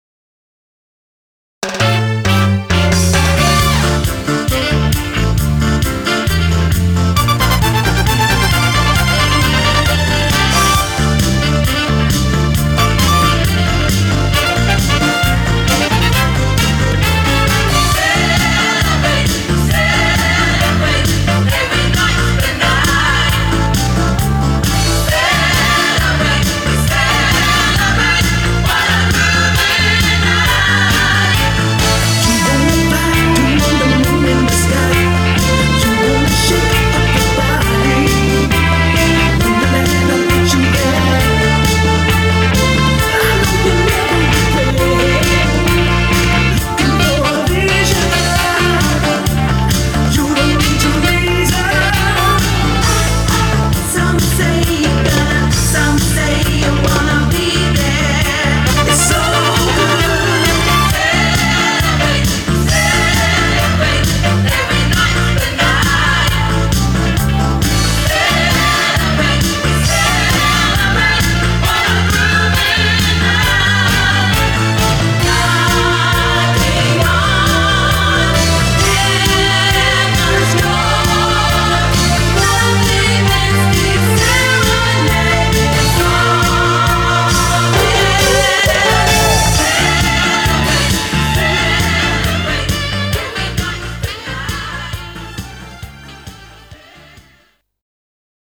BPM134
Audio QualityMusic Cut